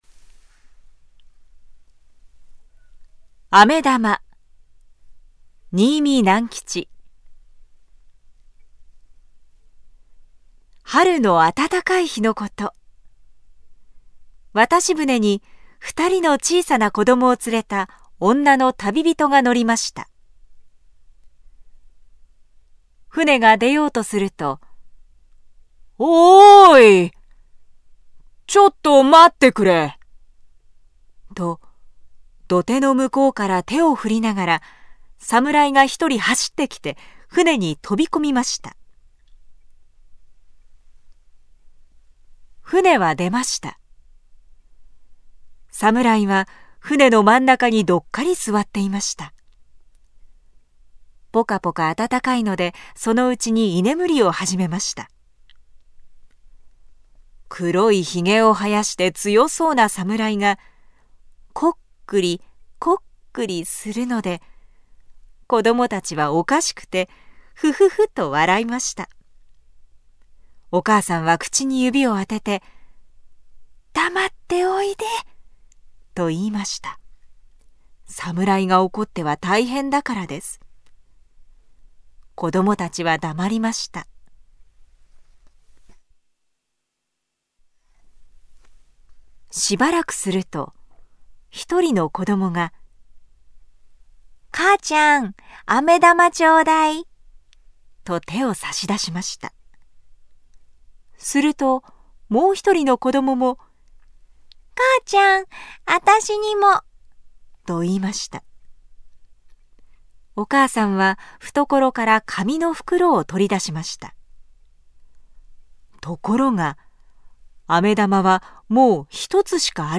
朗 読